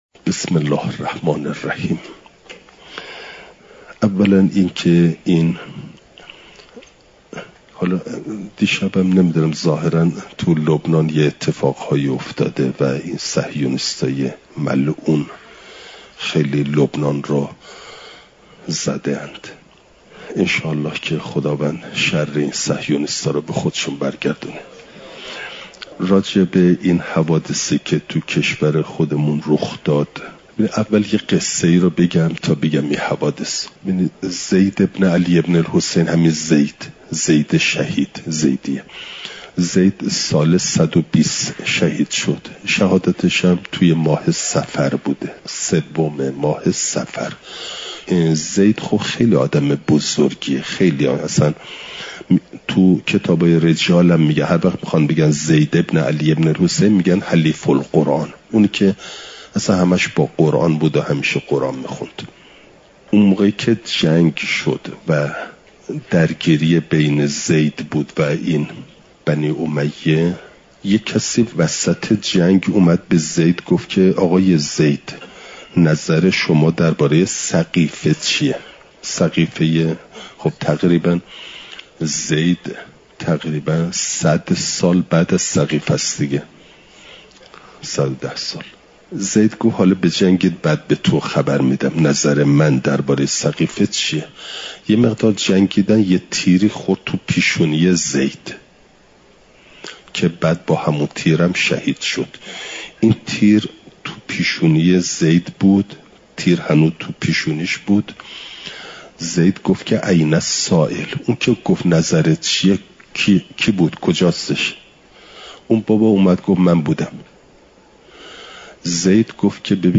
شنبه ۶ بهمن ماه ۱۴۰۴، حرم مطهر حضرت معصومه سلام ﷲ علیها